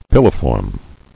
[pil·i·form]